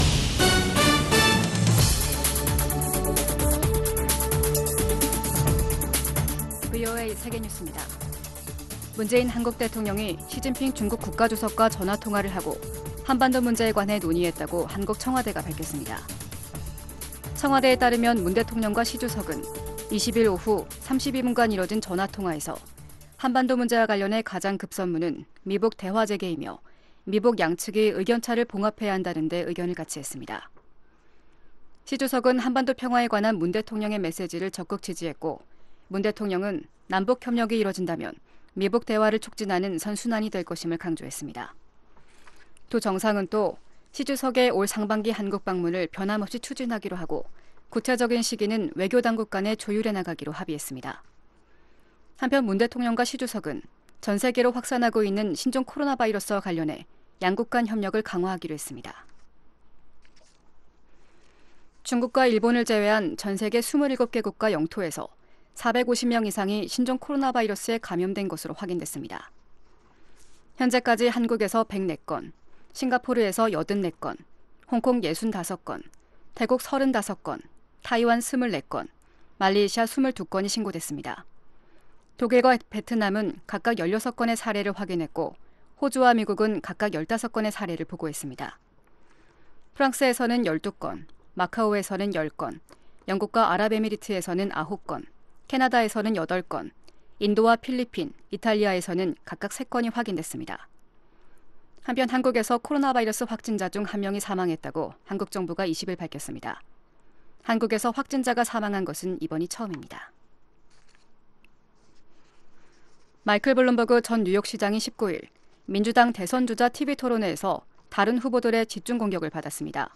VOA 한국어 아침 뉴스 프로그램 '워싱턴 뉴스 광장' 2020년 2월 21일 방송입니다. ‘지구촌 오늘’ 전 세계적으로 신종 코로나바이러스가 계속 확산하고 있는 가운데 한국에서 처음으로 사망자가 나왔다는 소식, ‘아메리카 나우’에서는 민주당 대선주자 9차 토론에서, 마이클 블룸버그 전 뉴욕시장에게 공격이 집중됐다는 이야기를 소개합니다.